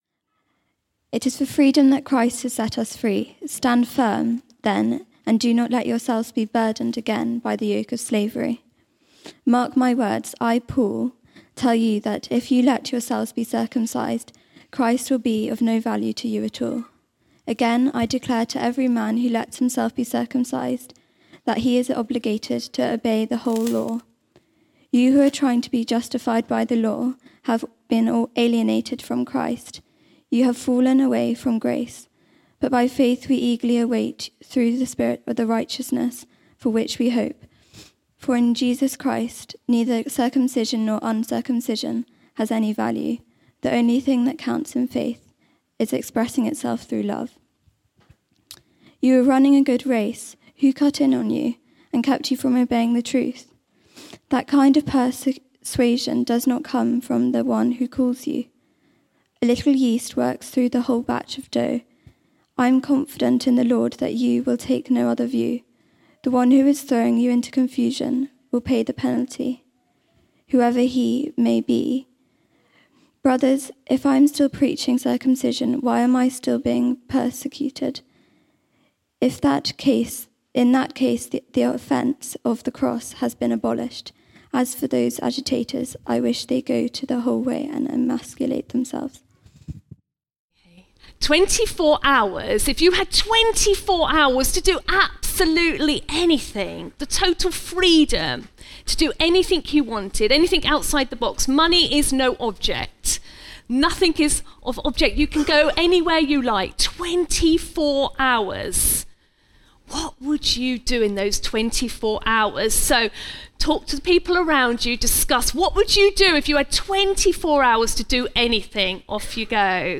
All Age Service